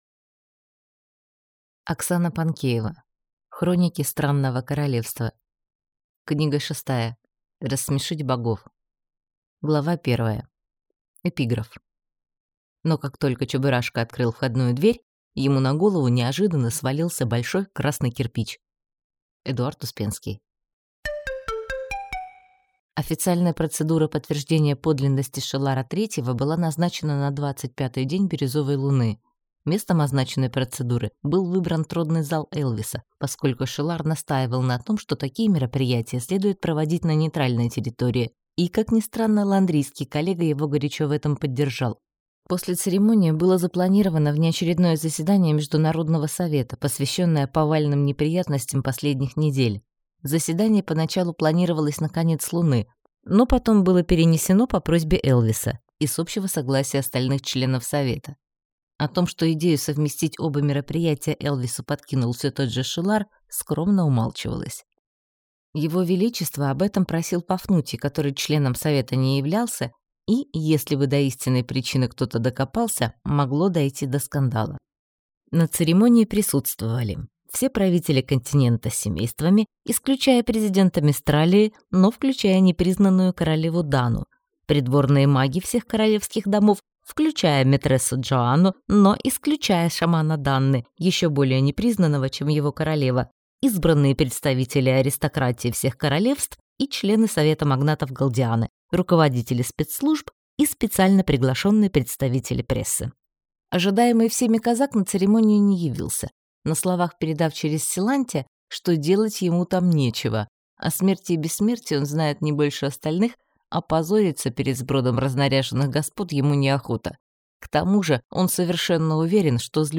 Аудиокнига Рассмешить богов | Библиотека аудиокниг